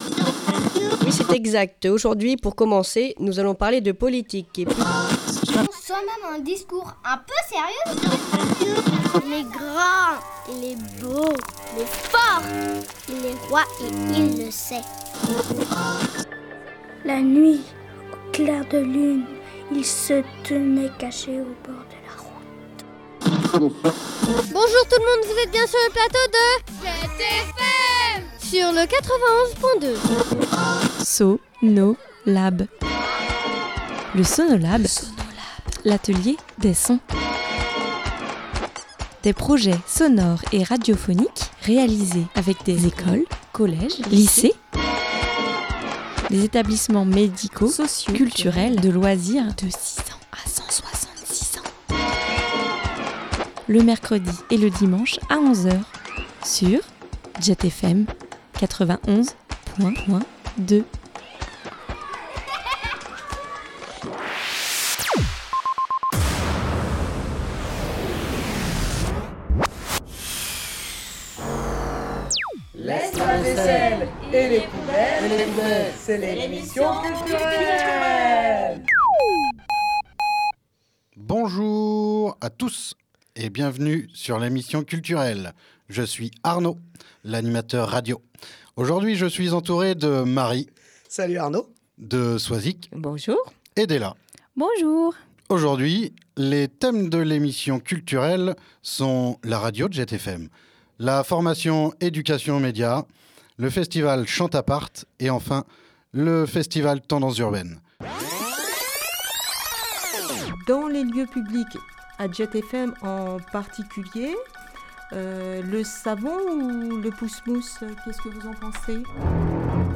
Ils ont pu réaliser 2 projets radiophoniques afin de s’approprier les différents outils (écriture, interview, enregistrement de voix, de bruitages, en studio, montage, …) :
une fiction sonore